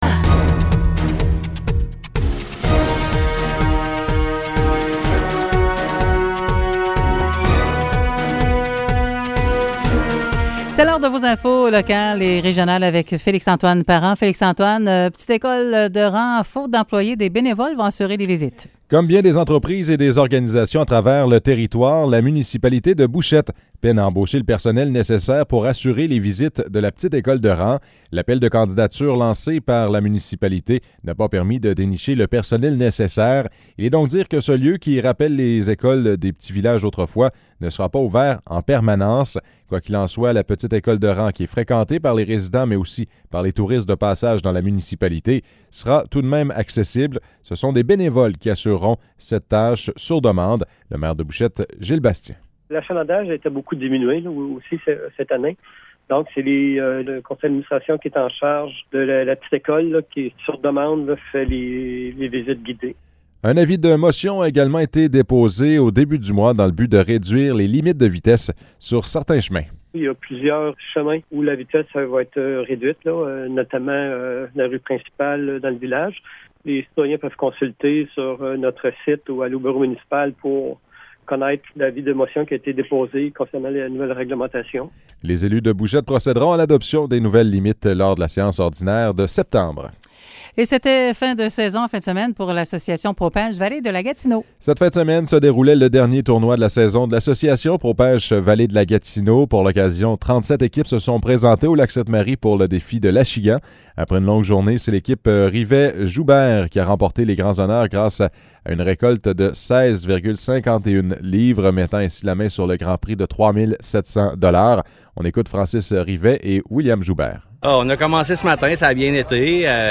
Nouvelles locales - 23 août 2021 - 10 h